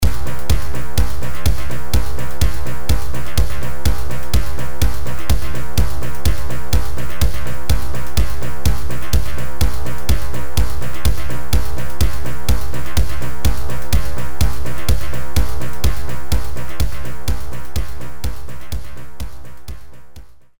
DL - Shooter Sound:
twi_shooter.mp3